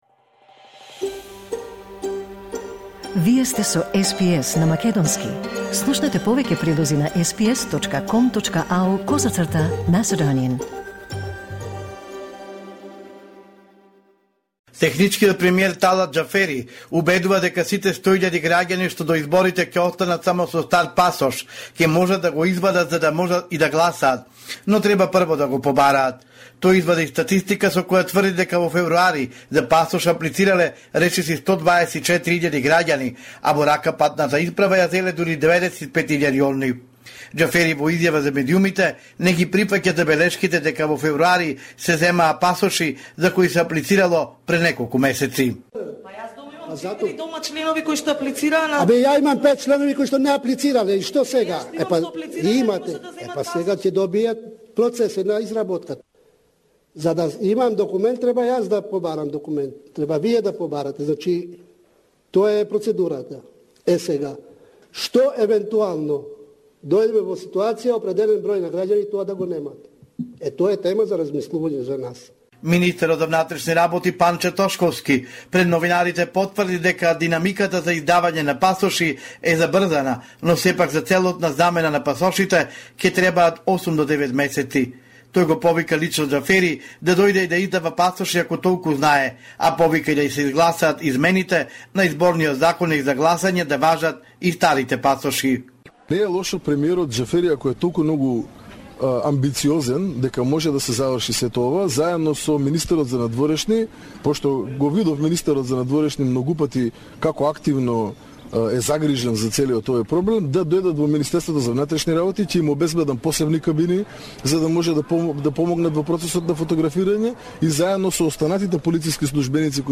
Homeland Report in Macedonian 7 March 2024